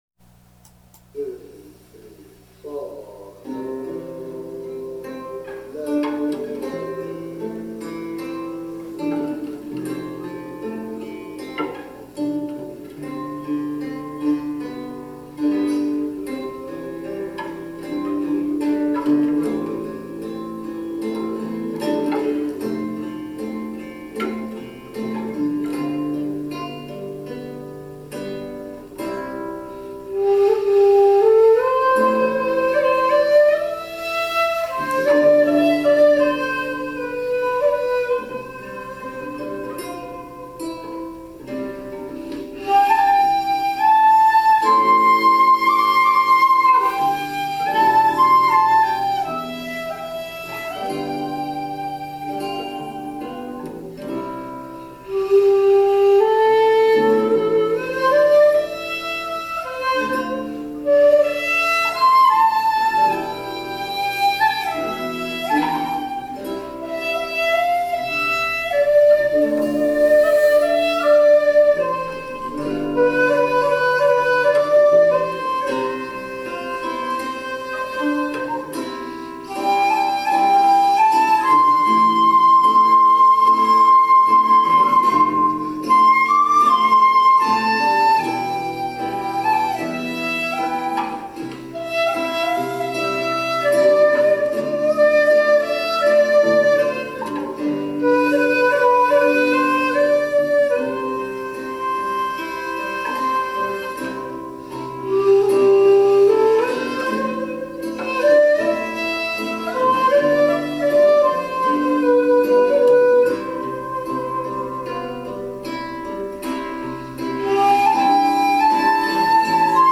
ベースギター、アコースティクギター、そして尺八での演奏です。リハの一コマですので、今回は気楽に聞いてください。
337-hana_rehearsal.mp3